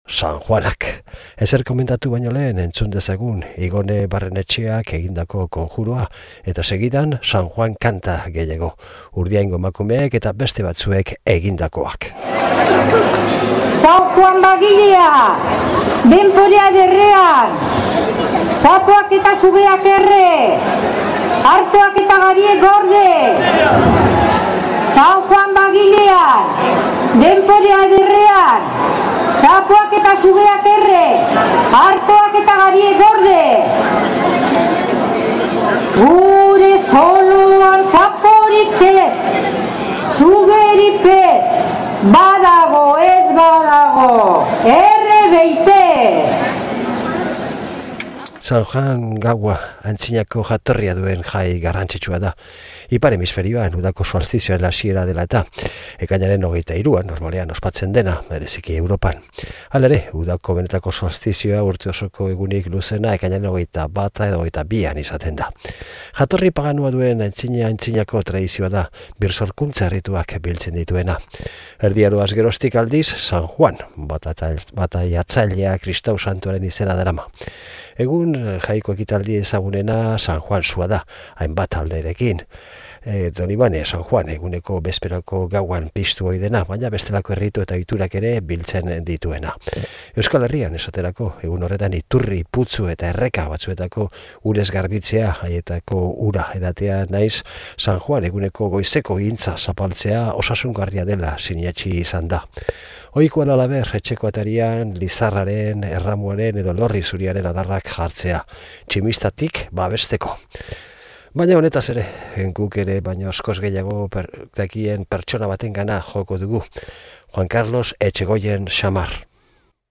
Eta bestetik, aurrean zein atzean, emakume batzuek egindako Konjuroa eta kanta bat. Sua eta ura nagusi gau honetan Euskal Herriko hainbat txokotan.